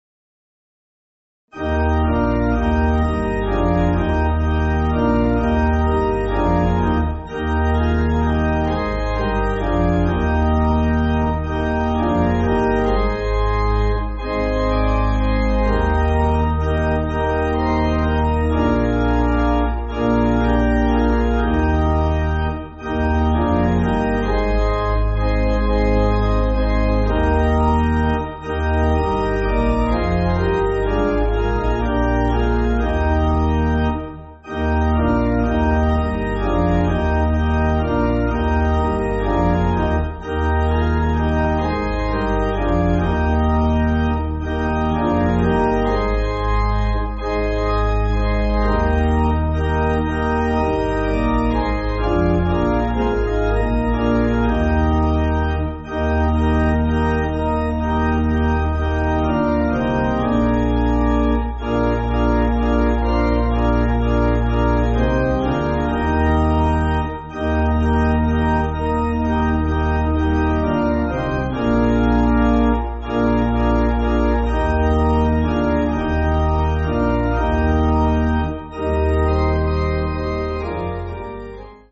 (CM)   1/Eb